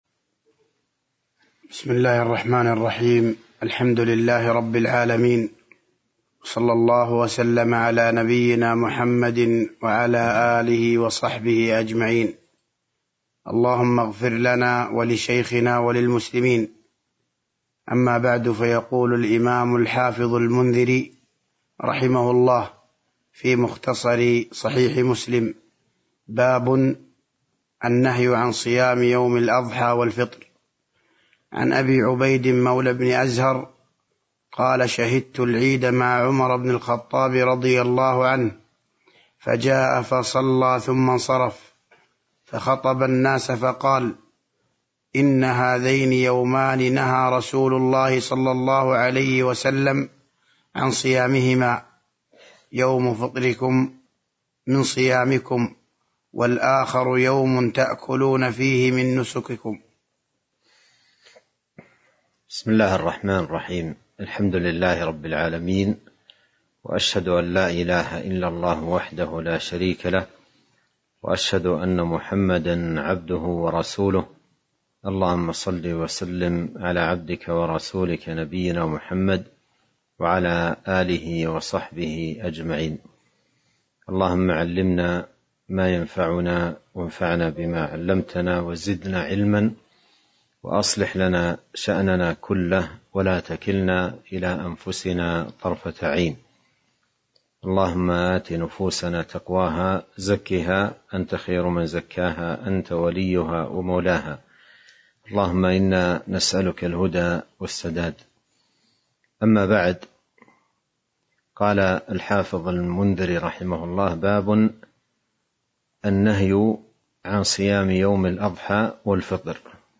تاريخ النشر ١٦ شعبان ١٤٤٢ هـ المكان: المسجد النبوي الشيخ: فضيلة الشيخ عبد الرزاق بن عبد المحسن البدر فضيلة الشيخ عبد الرزاق بن عبد المحسن البدر باب النهي عن صيام يوم الأضحى والفطر (09)